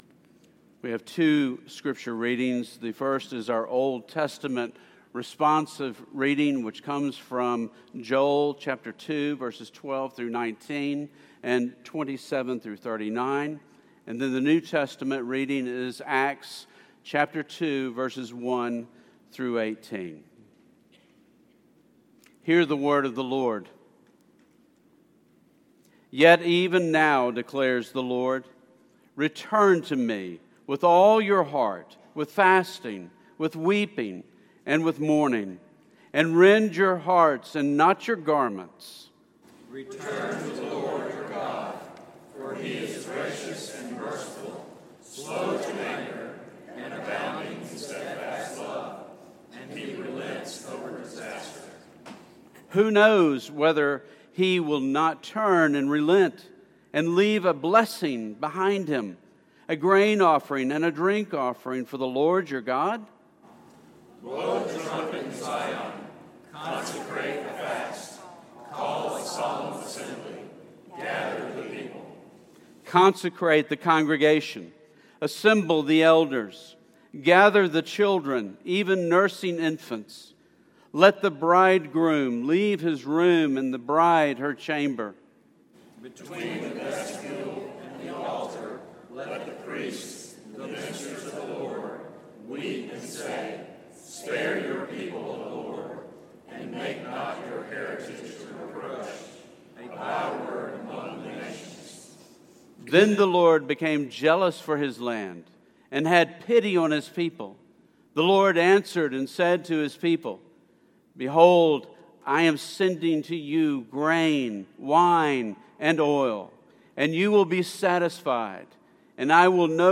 Sermons from Christ Covenant Reformed Church